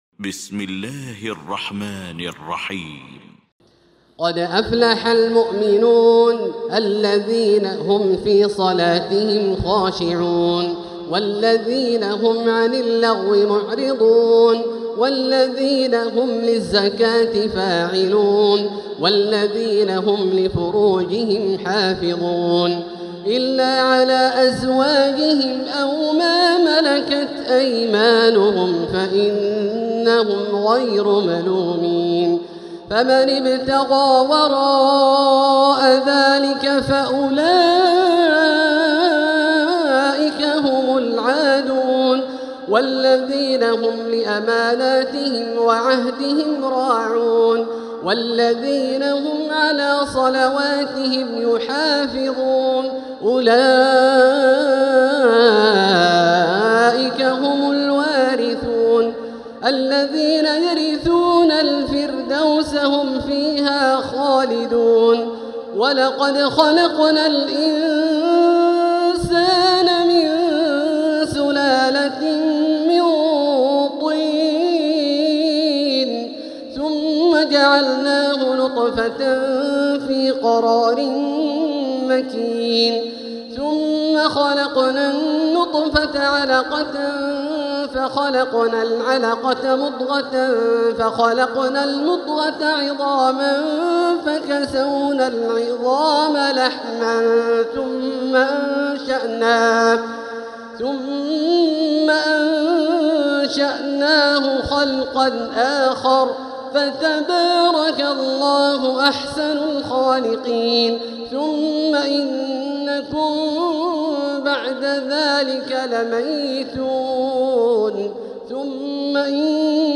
المكان: المسجد الحرام الشيخ: فضيلة الشيخ د. الوليد الشمسان فضيلة الشيخ د. الوليد الشمسان فضيلة الشيخ عبدالله الجهني المؤمنون